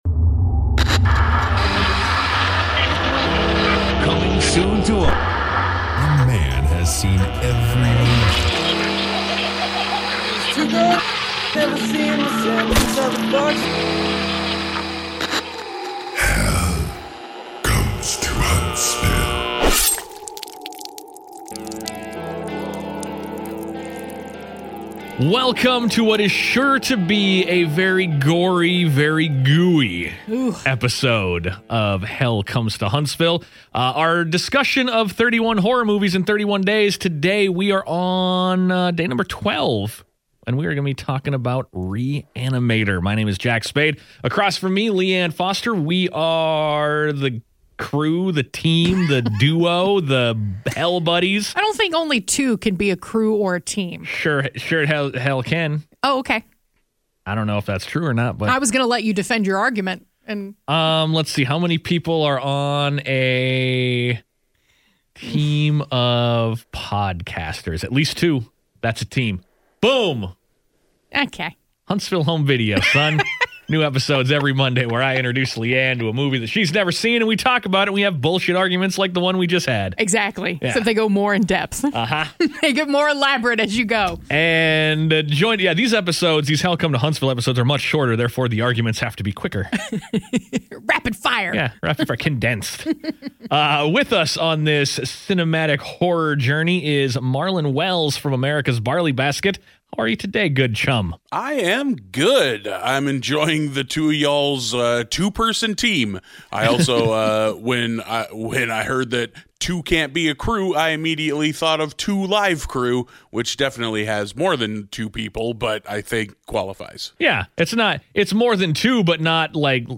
Tune into this gory, bloody, gooey review of Re-Animator with your three favorite chums!